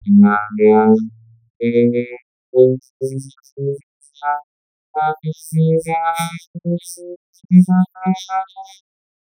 Wenn man als Synthesesignal (carrier) einen Sägezahn-Sweep nimmt und als Analysesignal (formant) ein Sprachbeispiel , dann zeigt sich insgesamt folgendes Ergebnis :
vocodiert.wav